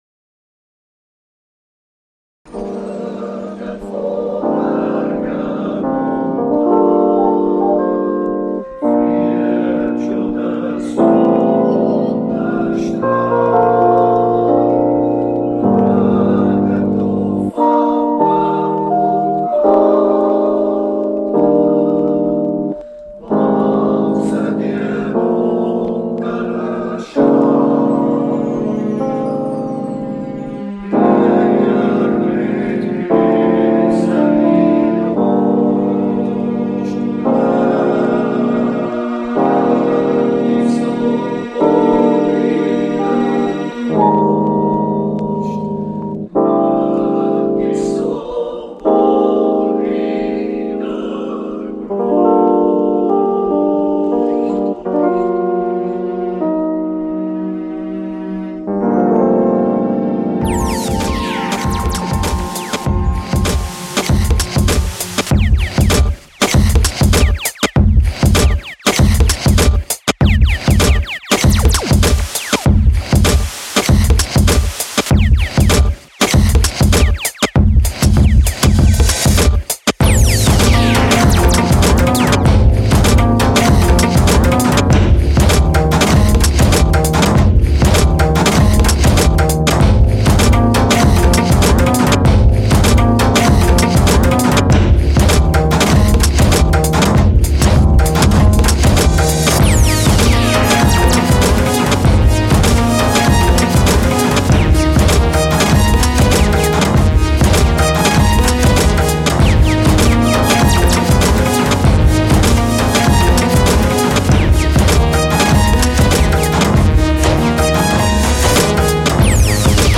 Indie International